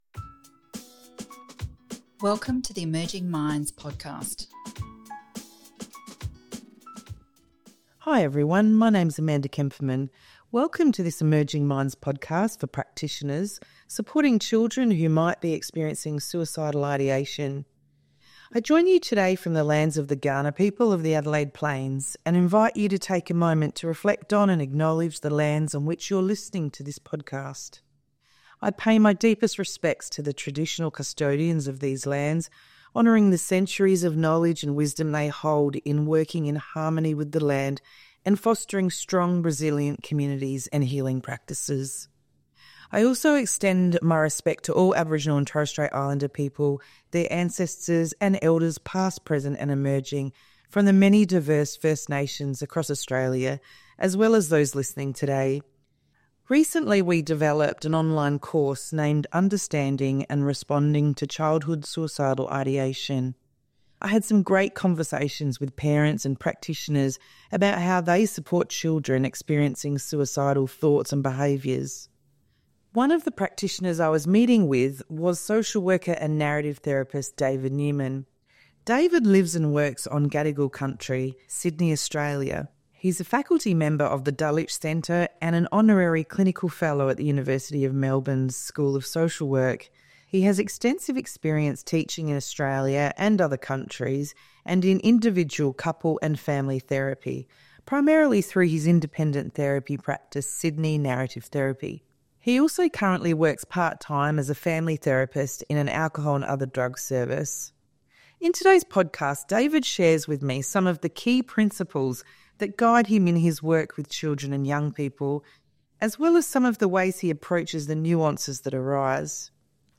Listen to conversations with experts on a variety of topics related to children's mental health.